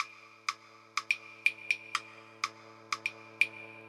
• techno groove hats.wav
tehcno_groove_hats_kHh.wav